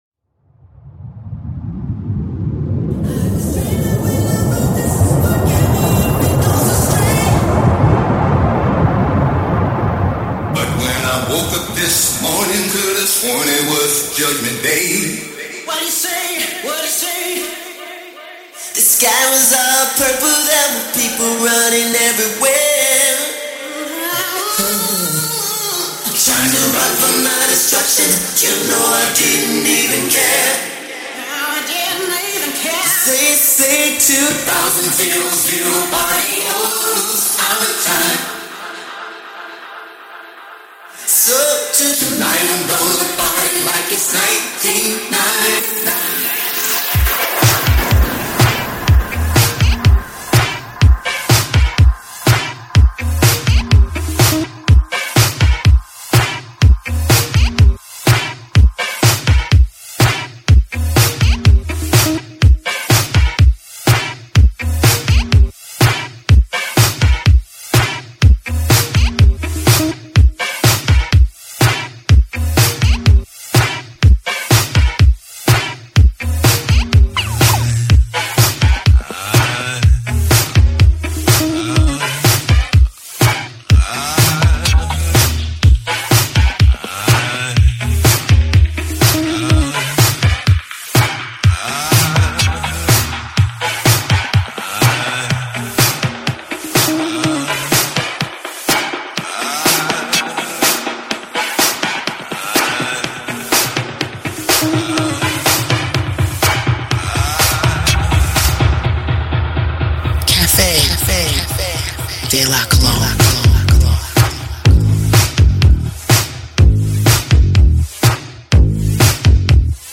Exclusive designer music mixed